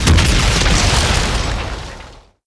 explo10_shake.wav